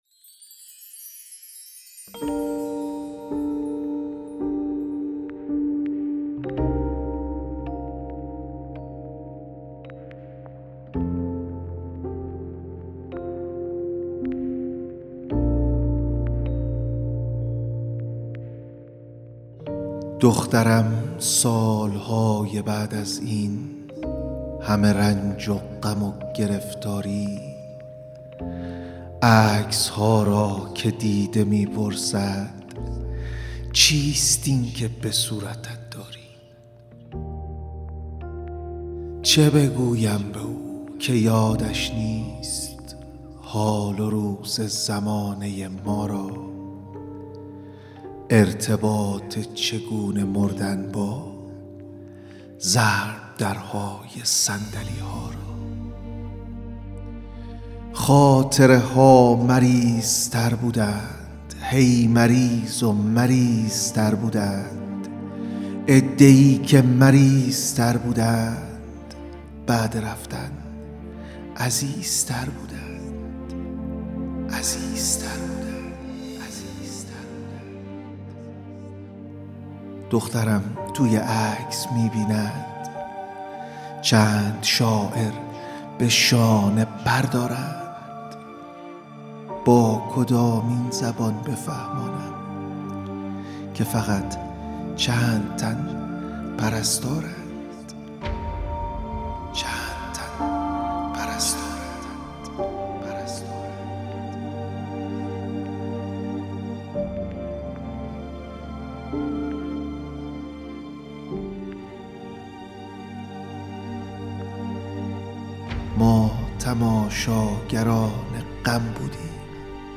دانلود دکلمه قرنطینه با صدا و قلم مهدی موسوی
اطلاعات دکلمه